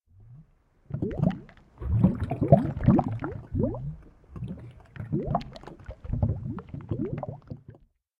latest / assets / minecraft / sounds / liquid / lava.ogg
lava.ogg